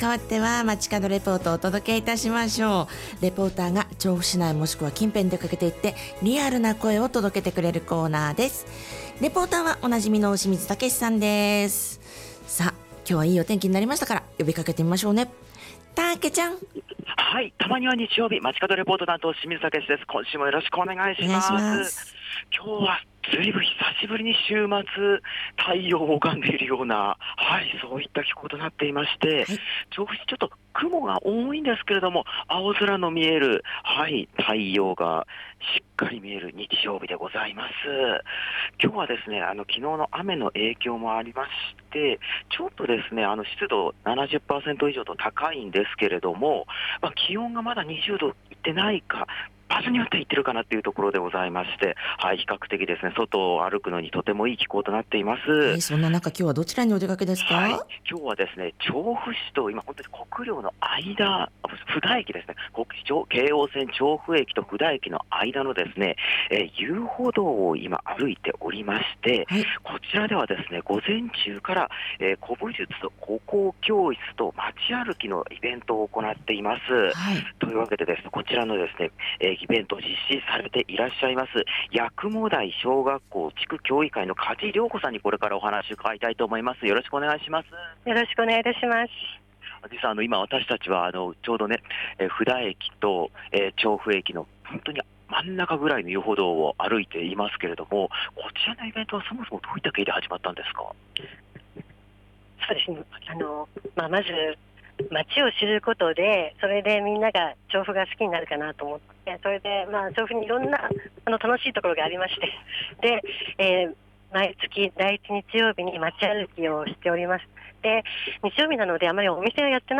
久々に晴れの週末の空の下からお届けした街角レポートは、調布駅と国領駅の間の線路跡遊歩道からお届けしました！